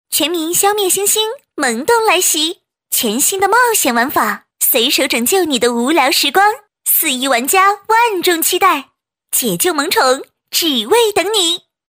女101-游戏广告【甜美活泼】
女101-游戏广告【甜美活泼】.mp3